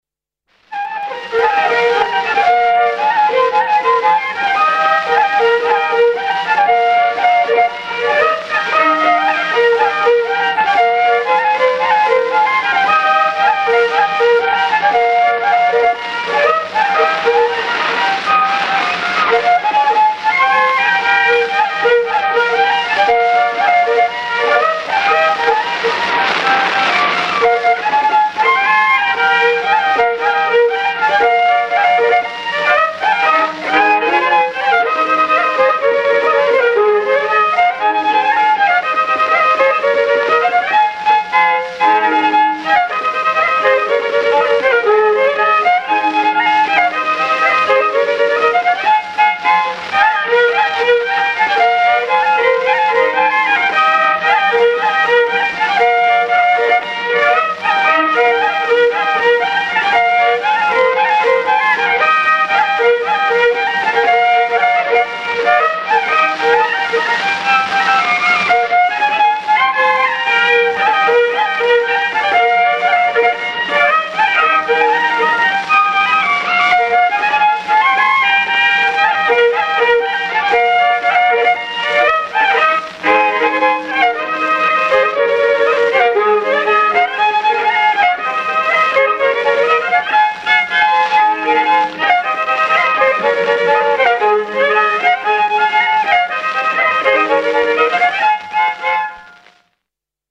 I viiul
II viiul